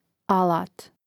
àlāt alat